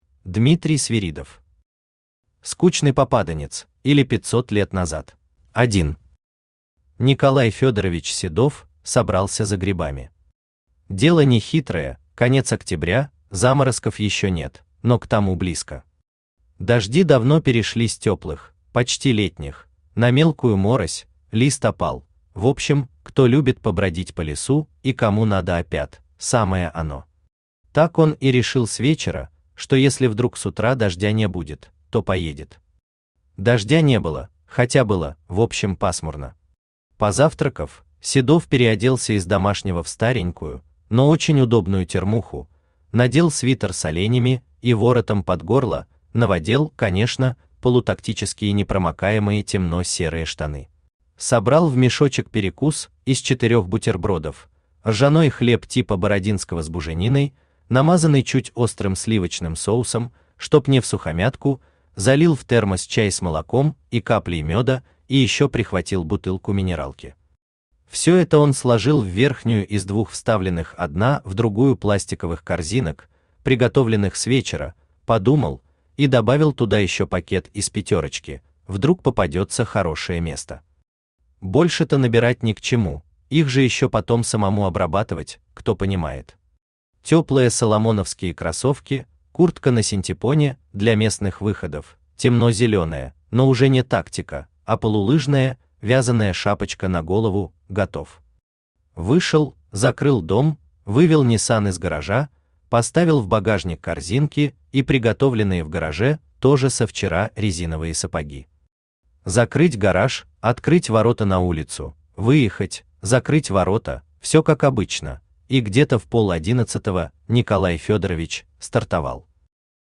Аудиокнига Скучный попаданец, или 500 лет назад | Библиотека аудиокниг
Aудиокнига Скучный попаданец, или 500 лет назад Автор Дмитрий Свиридов Читает аудиокнигу Авточтец ЛитРес.